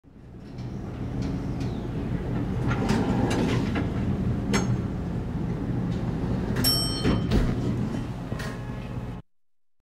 دانلود صدای آسانسور و زنگ ایستادن آسانسور از ساعد نیوز با لینک مستقیم و کیفیت بالا
جلوه های صوتی